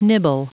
Prononciation du mot nibble en anglais (fichier audio)
Prononciation du mot : nibble
nibble.wav